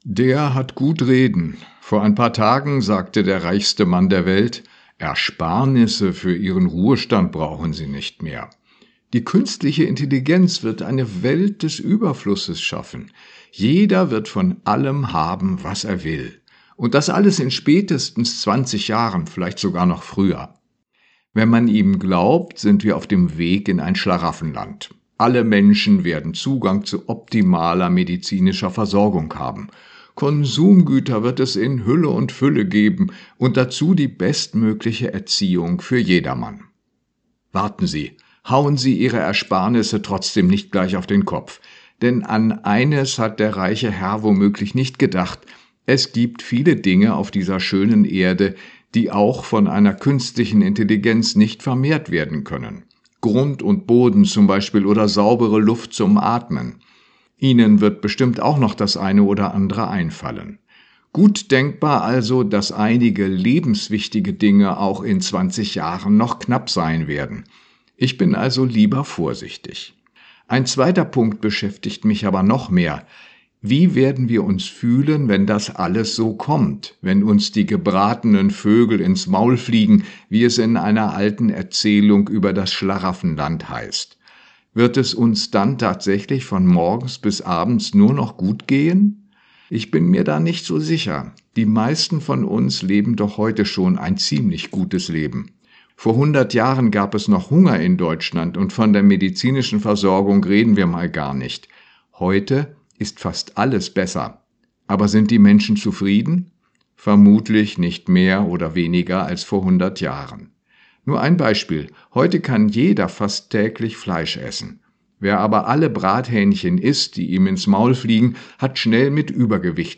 Radioandacht vom 15. Januar